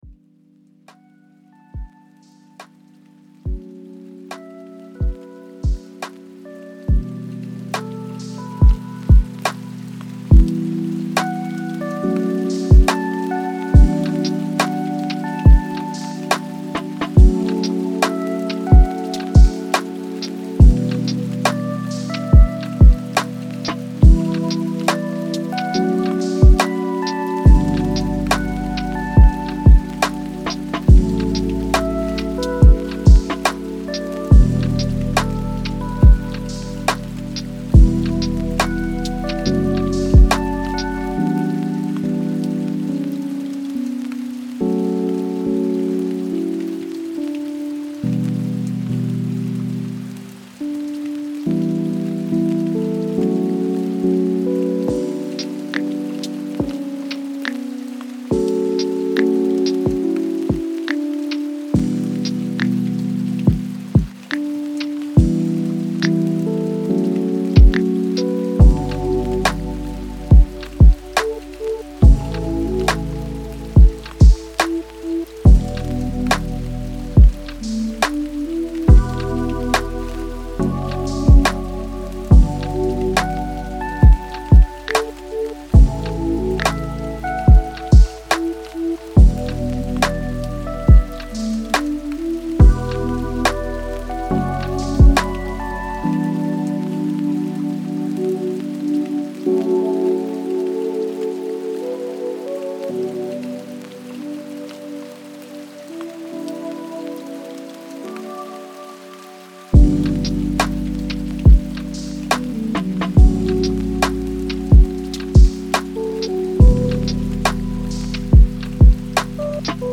Flux d’Étude 3h : Sons Naturels